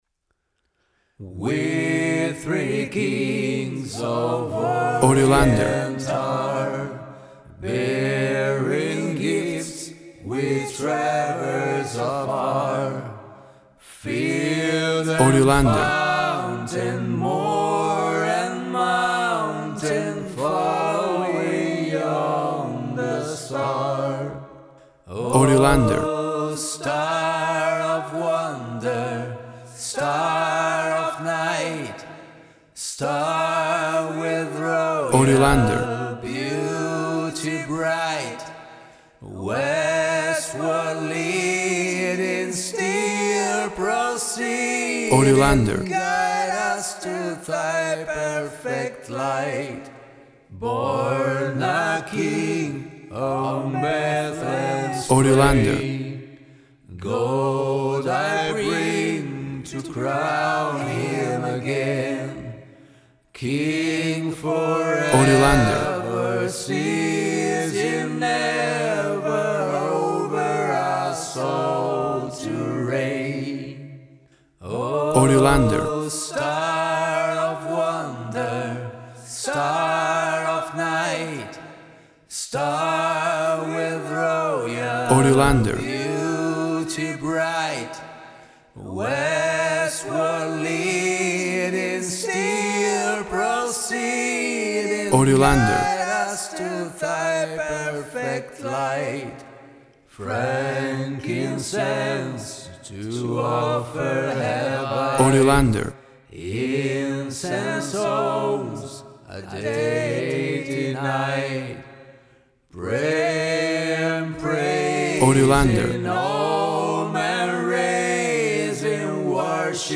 A beautiful vocal and voice only arrangement
Full of happy joyful festive sounds and holiday feeling!.
Tempo (BPM) 120